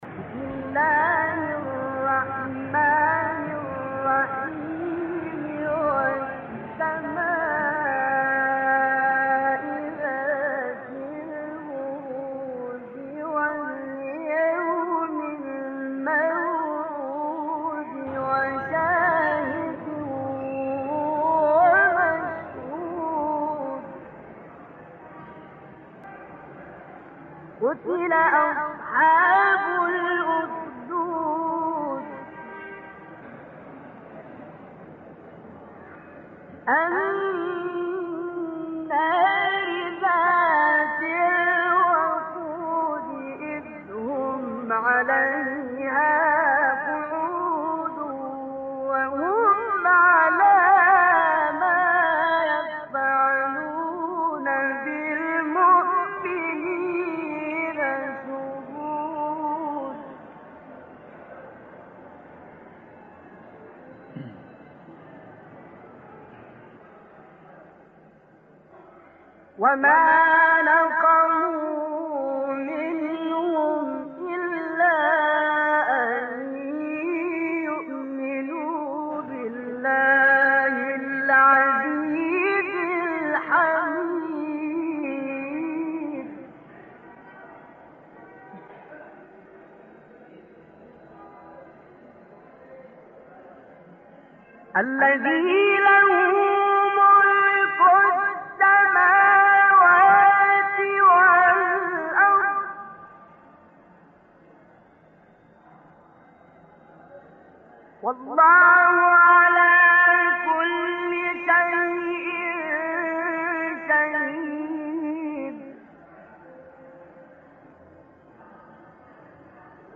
مقام رست استاد طنطاوی سوره بروج | نغمات قرآن | دانلود تلاوت قرآن